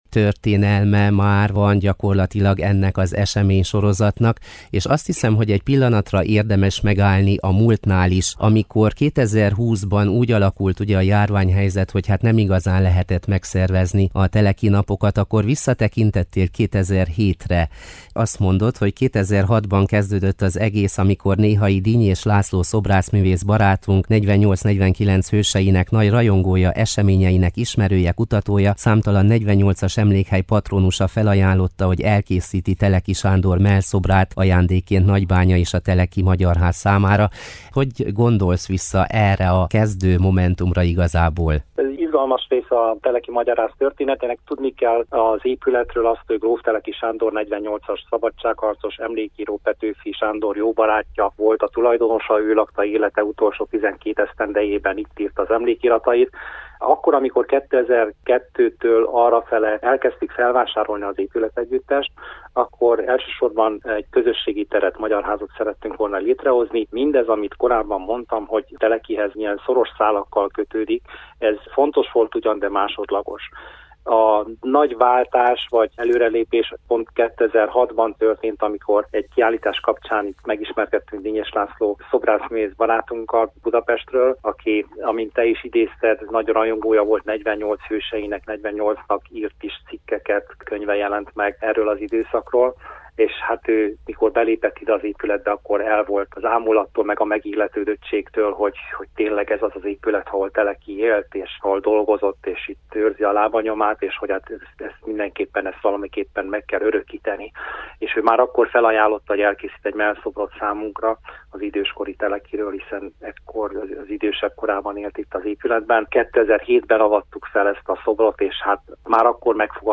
Beszélgetőtárs: